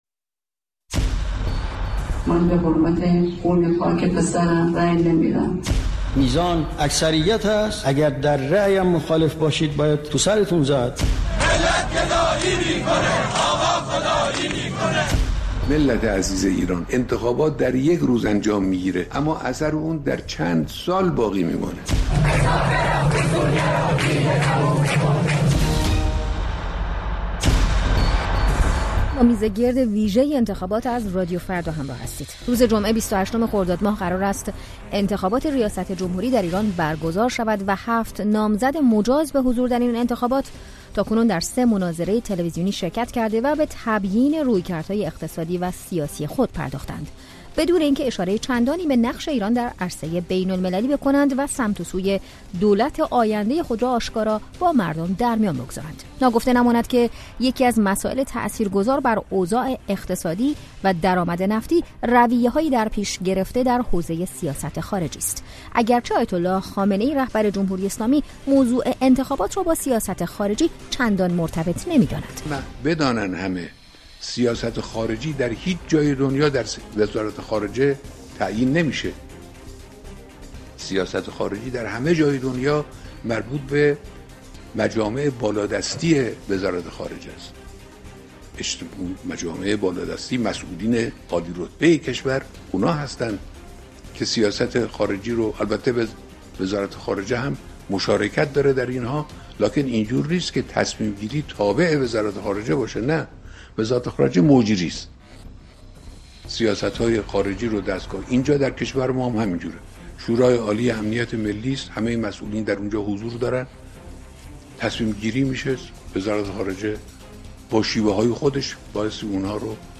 میزگرد ویژه انتخابات: سیاست خارجی و انتخابات